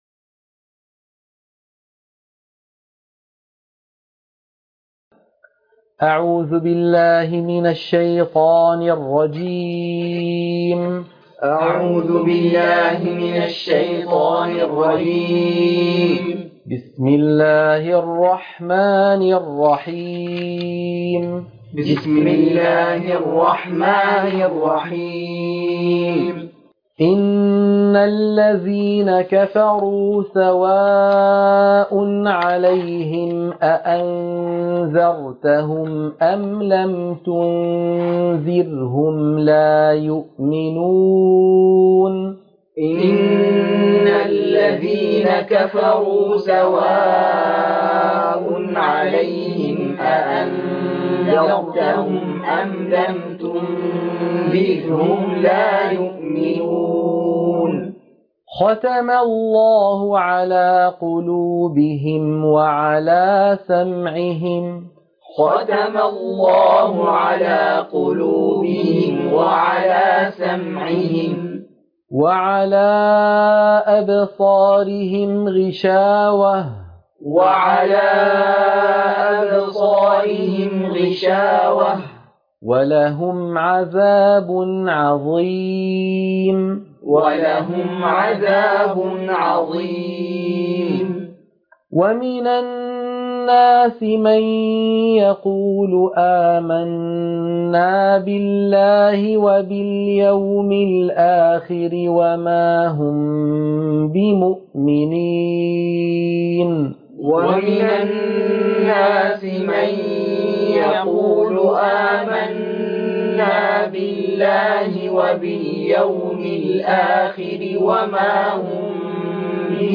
تلقين سورة البقرة - الصفحة 3 _ التلاوة المنهجية - الشيخ أيمن سويد